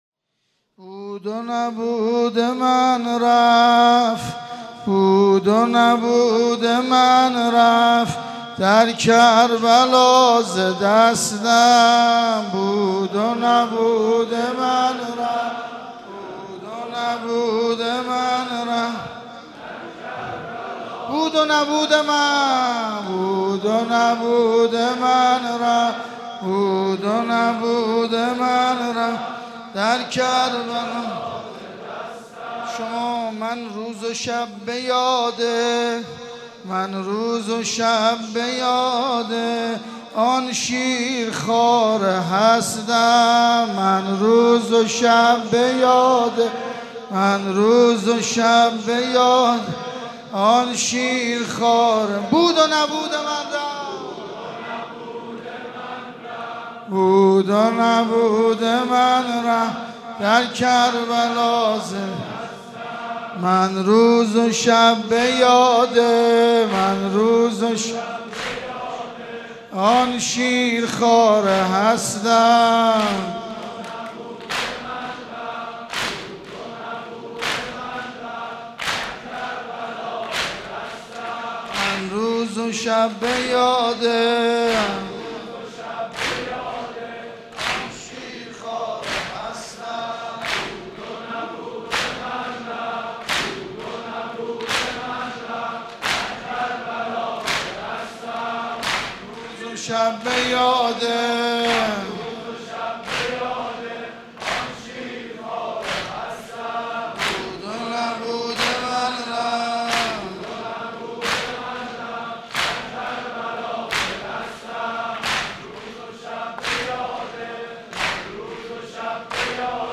شب چهارم محرم95/مسجد حضرت امیر (ع)